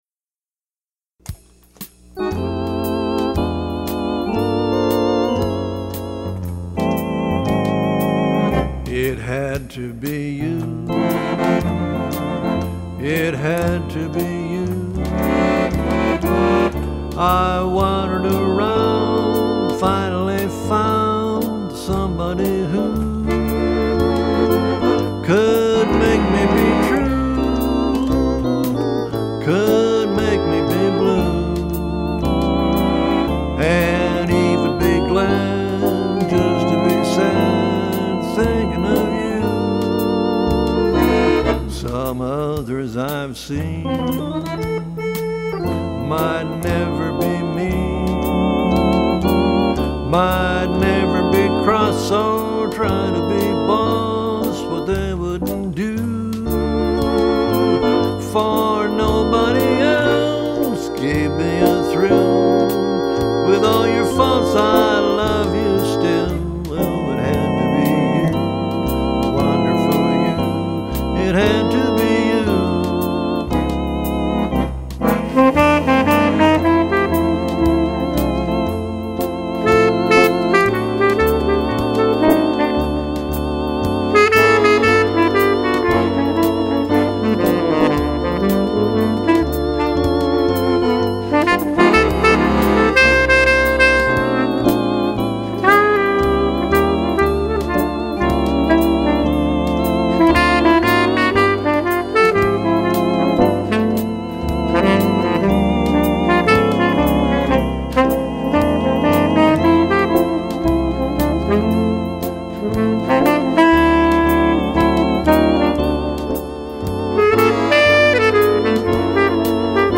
easy listening bossa novas, ballads, and jazz standards